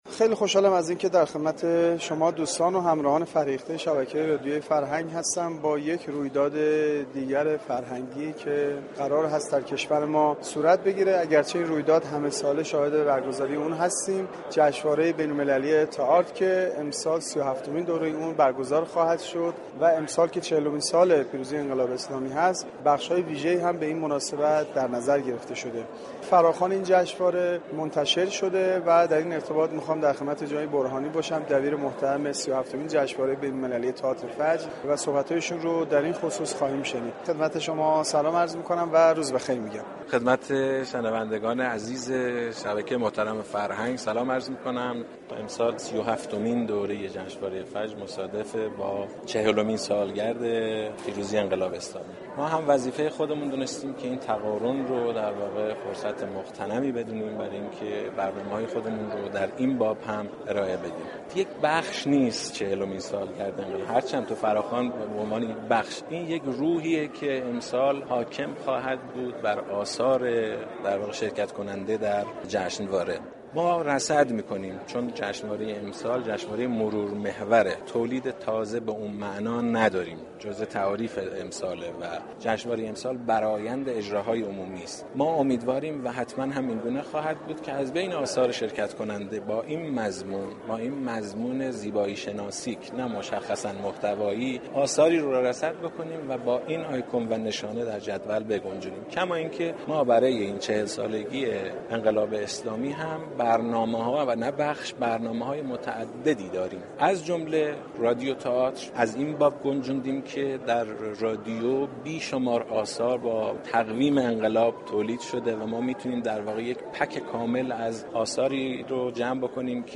در گفتگو با گزارشگر رادیو فرهنگ